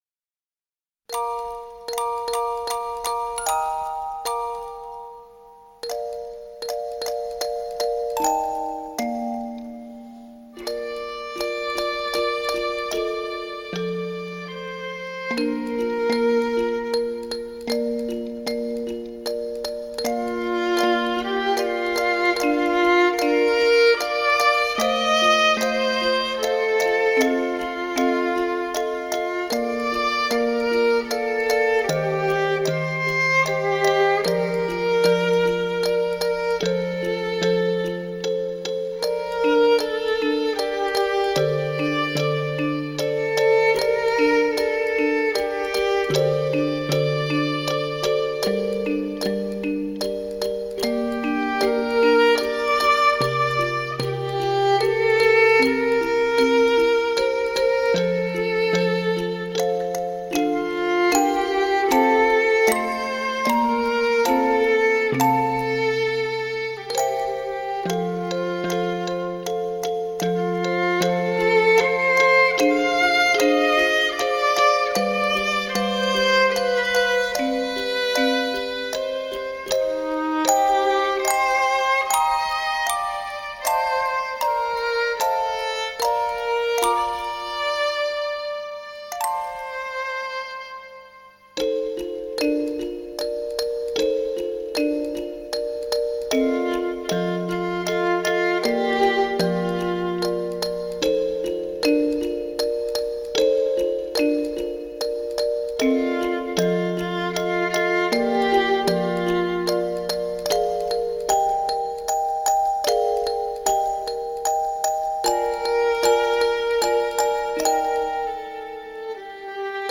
🎶 Детские песни / Музыка детям 🎵 / Музыка для новорожденных